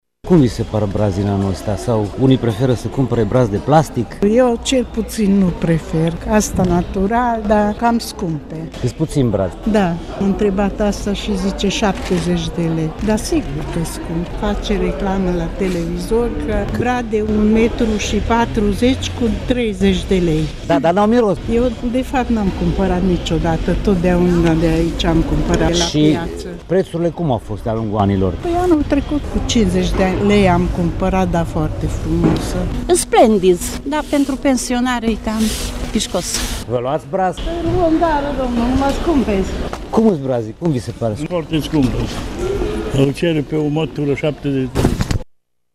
Deocamdată clienții spun că testează piața, studiază prețurile, mai ales că în hipermarketuri pot găsi brazi mult mai frumoși, la prețuri accesibile: